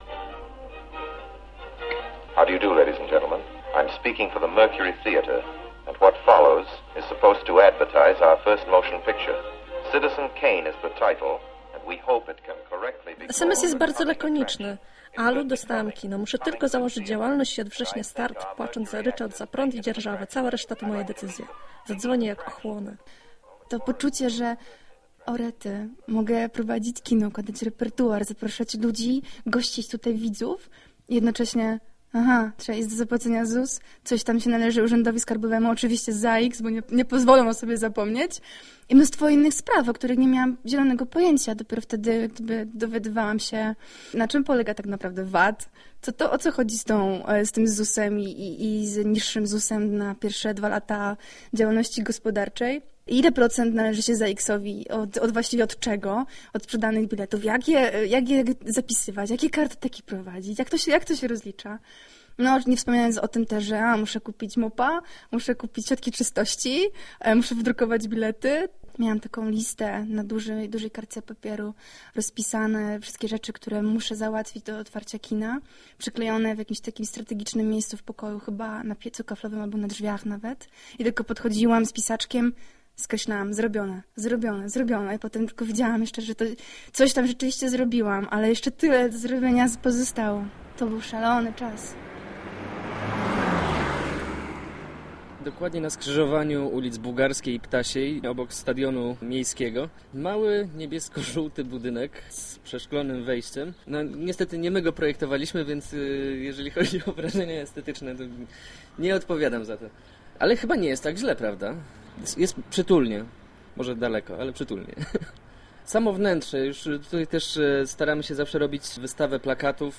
Orbis Pictus - reportaż